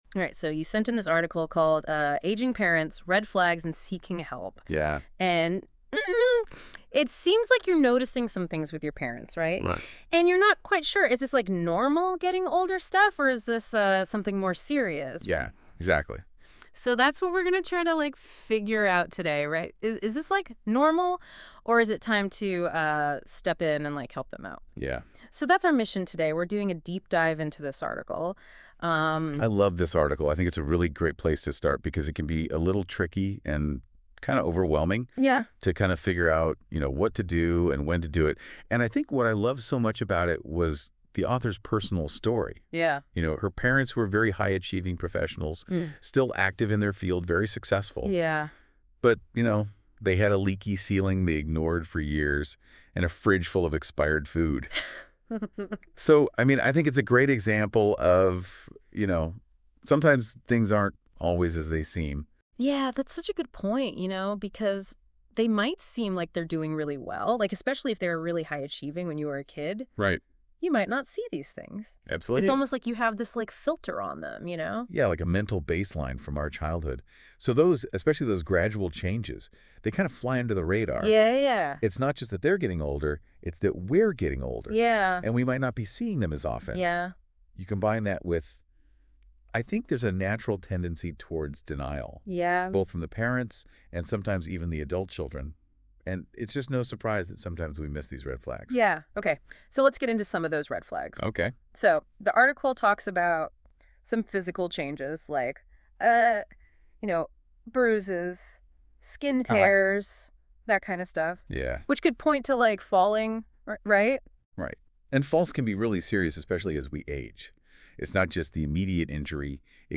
Click to listen to an audio conversation about this topic: